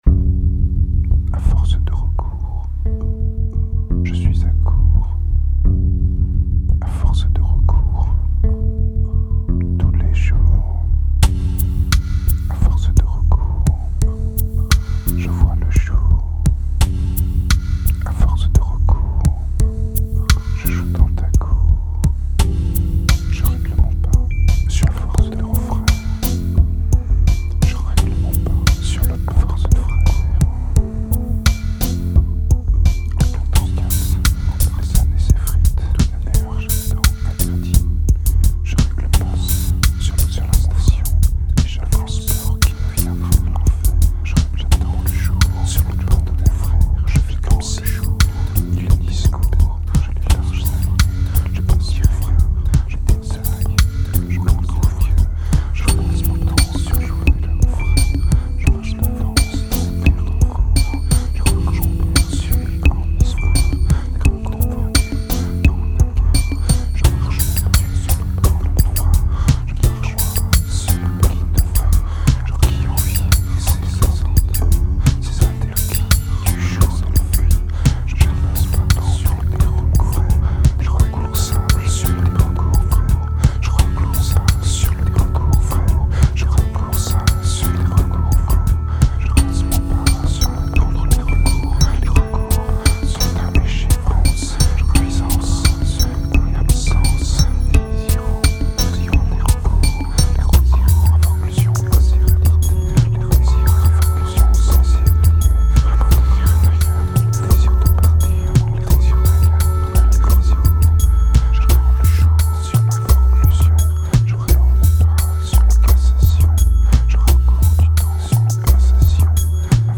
Tight and progressive, it seems to flow out more naturally.
2293📈 - -37%🤔 - 86BPM🔊 - 2008-11-01📅 - -318🌟